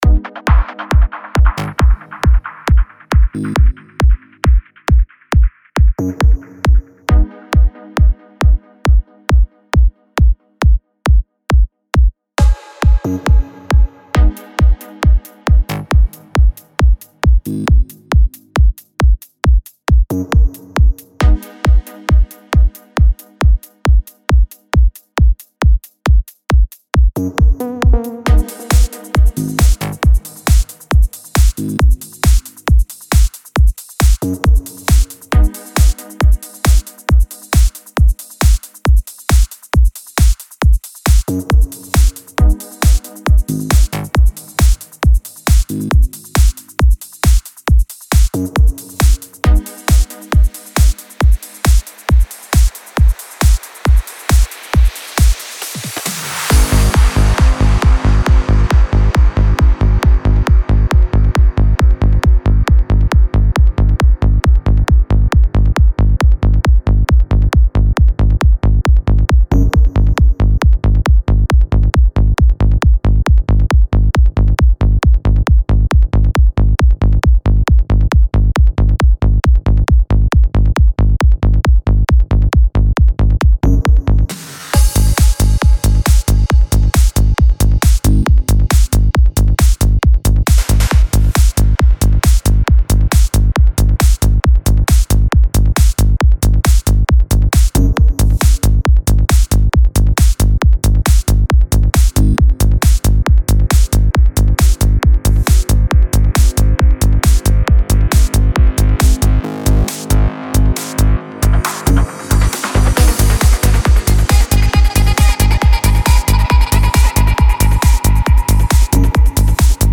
Клубная музыка
транс музыка
клубная музыка